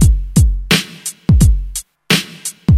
• 86 Bpm Drum Loop G Key.wav
Free breakbeat - kick tuned to the G note. Loudest frequency: 1514Hz
86-bpm-drum-loop-g-key-QSC.wav